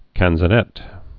(kănzə-nĕt)